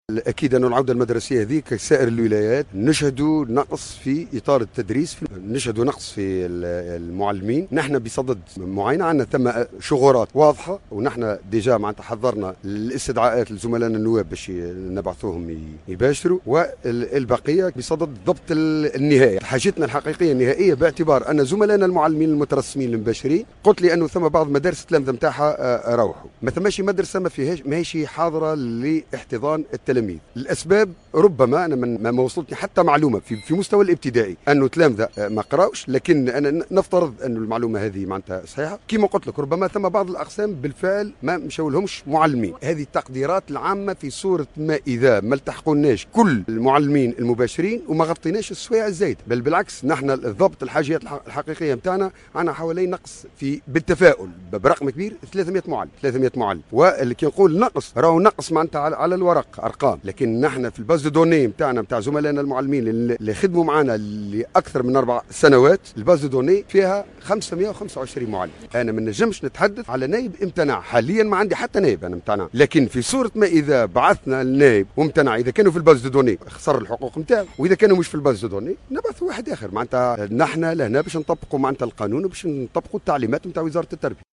أكد المندوب الجهوي للتربية بالمهدية المنجي منصر في تصريح للجوهرة أف أم اليوم الجمعة 16 سبتمبر 2016 أن المؤسسات التربوية في الجهة تشهد على غرار بقية الولايات نقصا في الاطار التربوي ما عطل نوعا ما الانطلاق العادي للعودة المدرسية بالجهة.